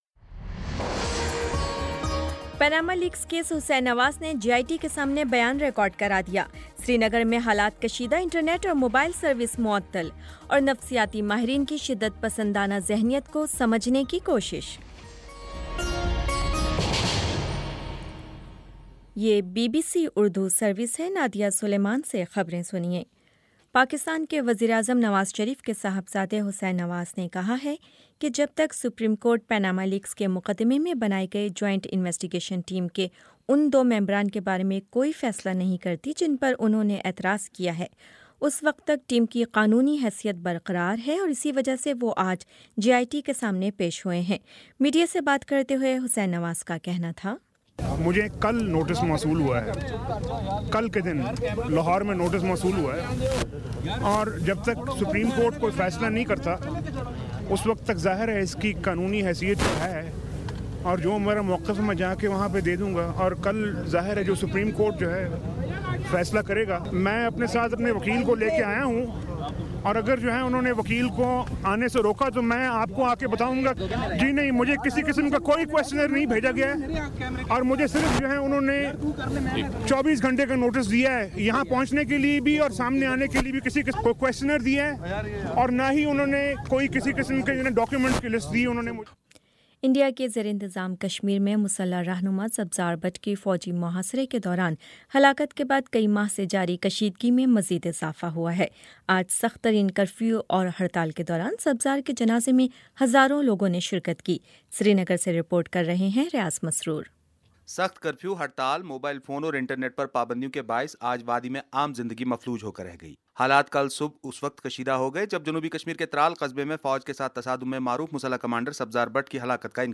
مئی 28 : شام سات بجے کا نیوز بُلیٹن